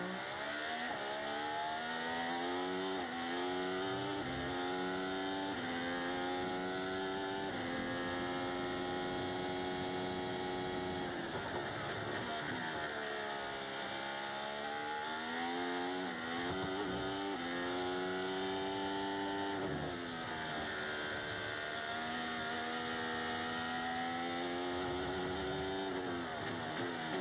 Team Radio (1)